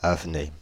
Avenay (French pronunciation: [avnɛ]
Fr-Avenay.ogg.mp3